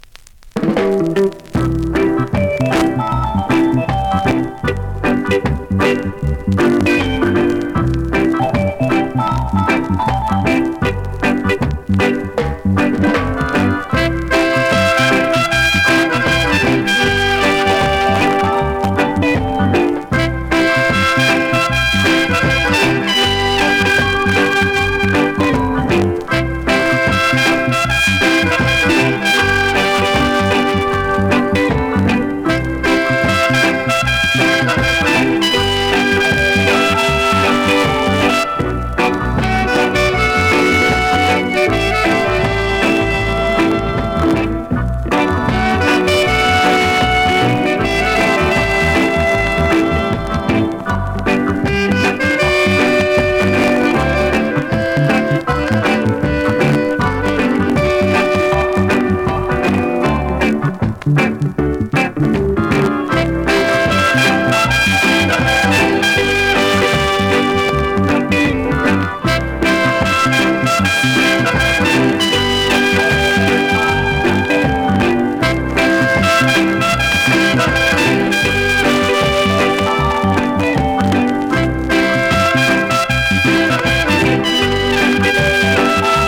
コンディションVG+(少しプチプチ)
スリキズ、ノイズ比較的少なめで